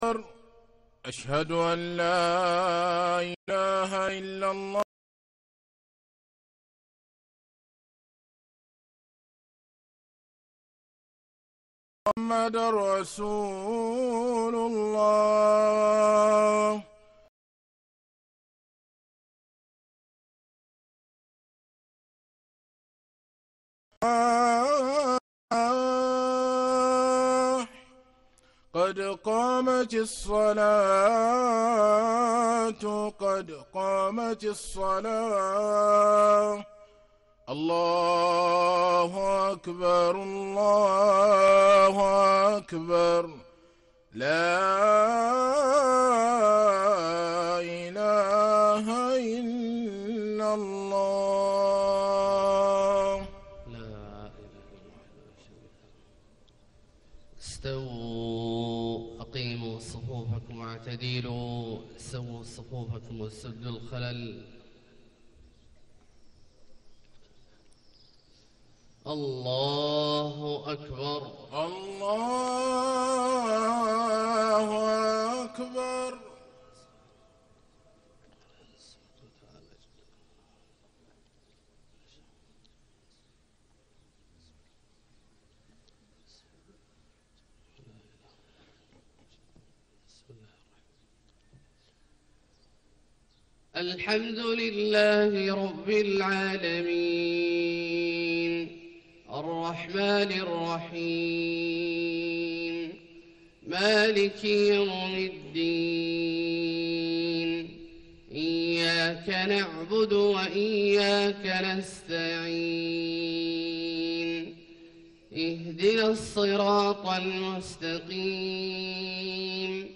صلاة الفجر 1-3-1437هـ من سورة آل عمران {33-51} > 1437 🕋 > الفروض - تلاوات الحرمين